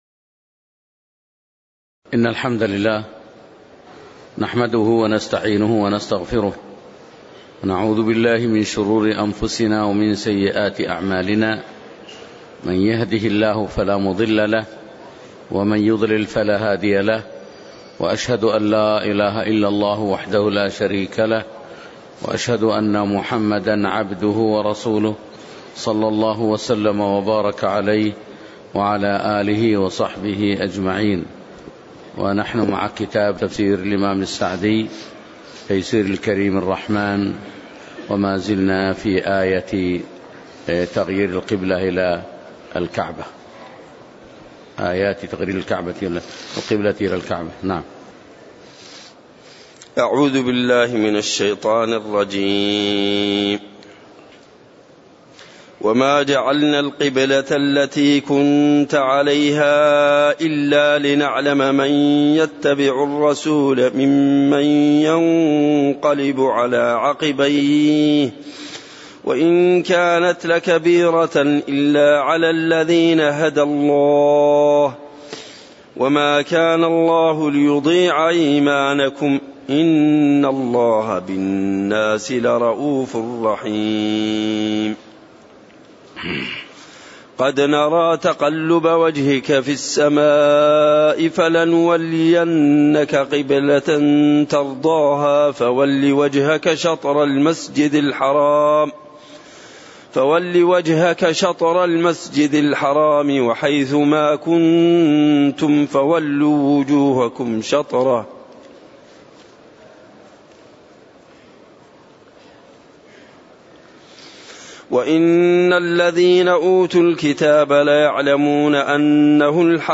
تاريخ النشر ٥ جمادى الآخرة ١٤٣٨ هـ المكان: المسجد النبوي الشيخ